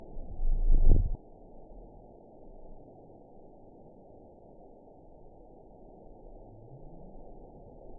event 911691 date 03/06/22 time 17:06:02 GMT (3 years, 2 months ago) score 9.64 location TSS-AB04 detected by nrw target species NRW annotations +NRW Spectrogram: Frequency (kHz) vs. Time (s) audio not available .wav